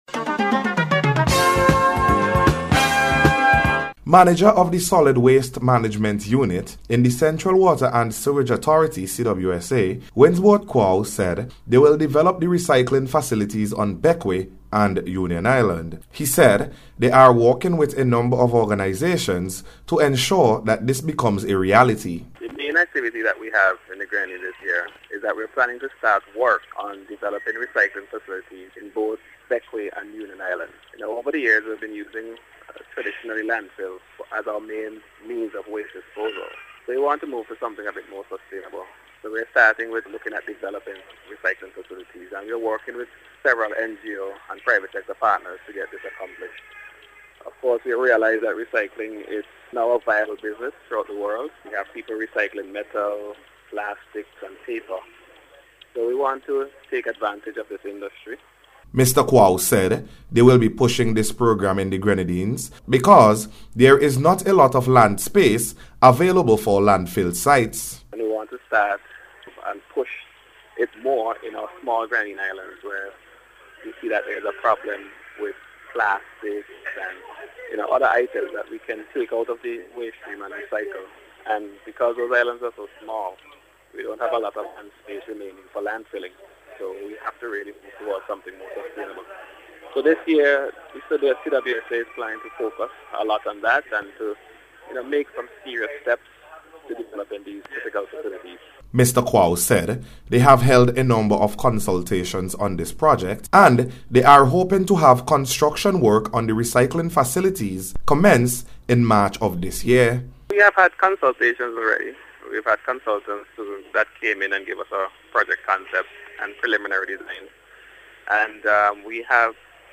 GRENADINES-RECYCLING-REPORT.mp3